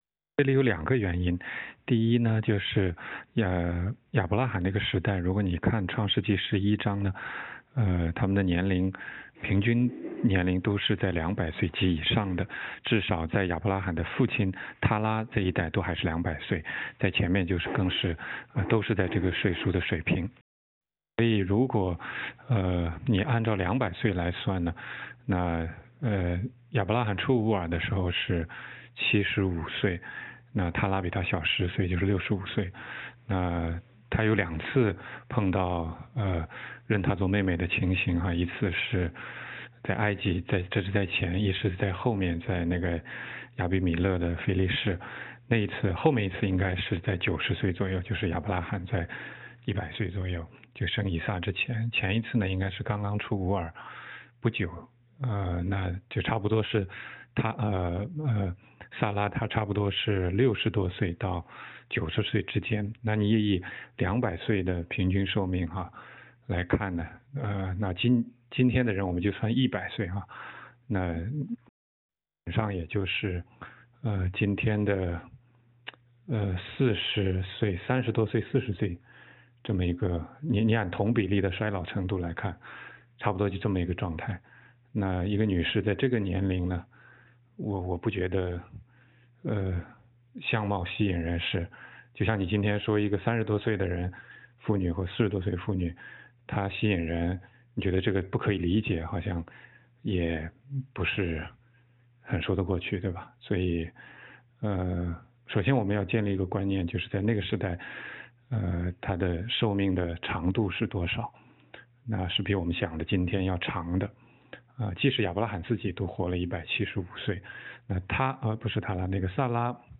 16街讲道录音 - 90多岁的撒拉能有多漂亮，还值得王要她做老婆吗?